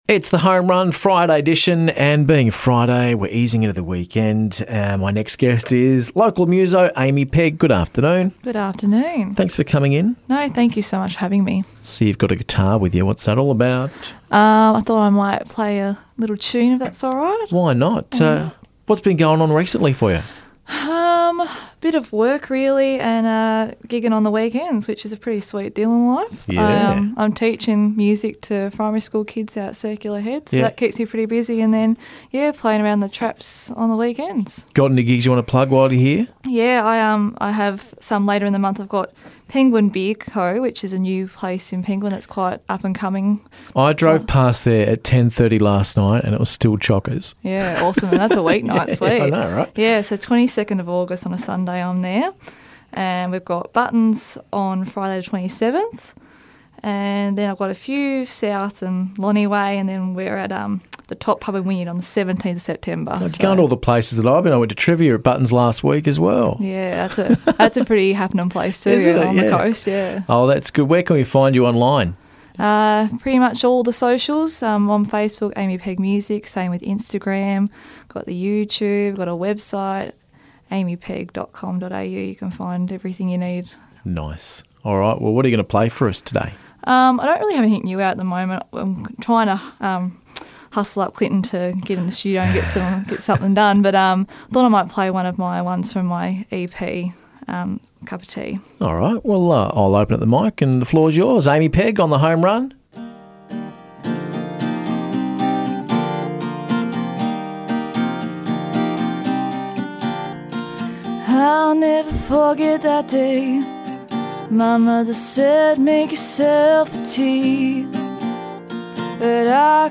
live in studio
play some live music